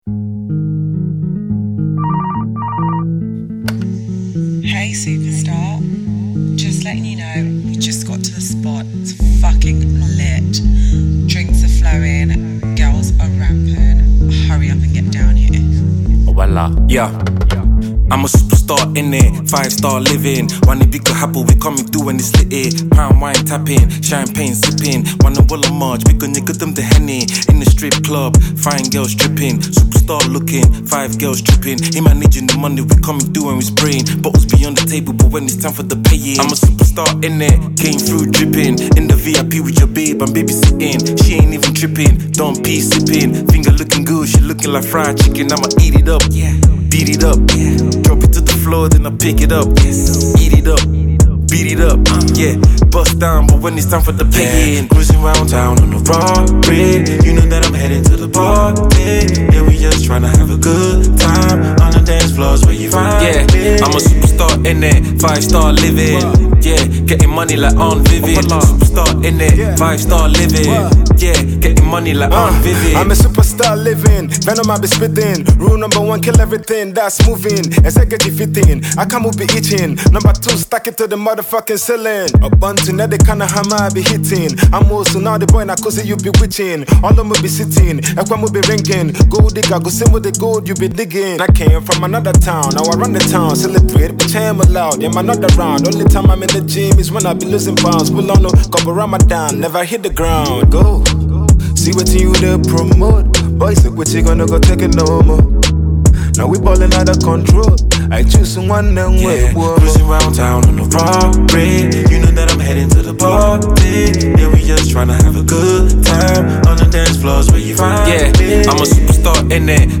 Afro hip-hop
infectious hip-hop bop